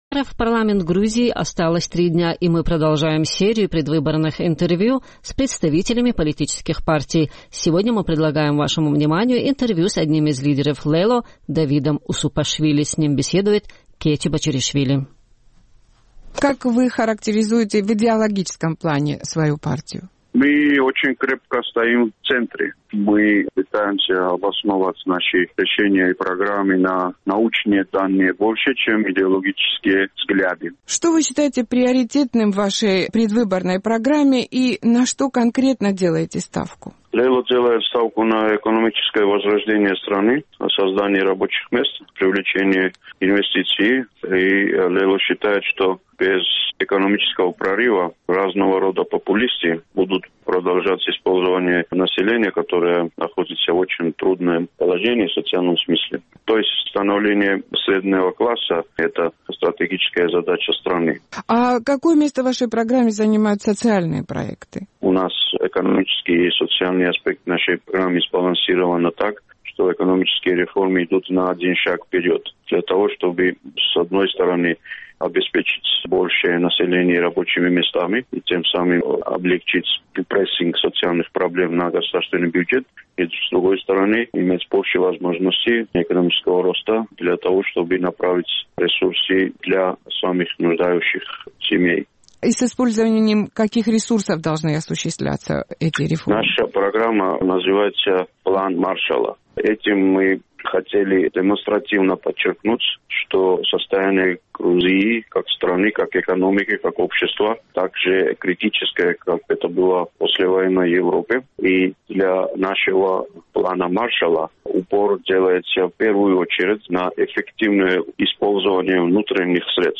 В серии предвыборных интервью сегодня мы предлагаем беседу с одним из лидеров «Лело» Давидом Усупашвили.